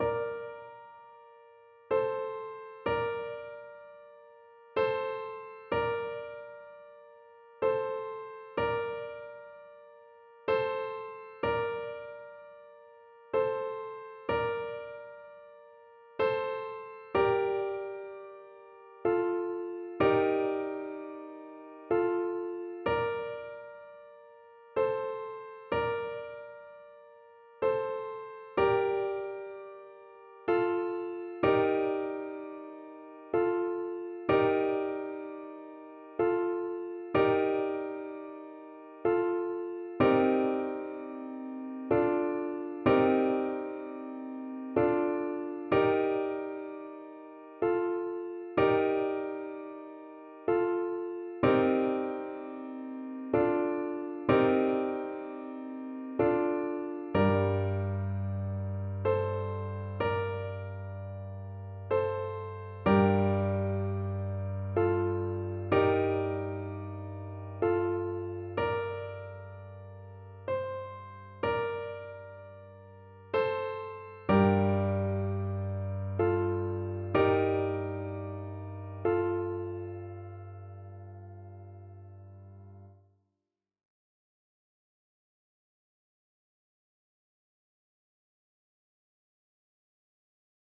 Flute
F major (Sounding Pitch) (View more F major Music for Flute )
9/8 (View more 9/8 Music)
Traditional (View more Traditional Flute Music)
Irish
butterfly_FL_kar3.mp3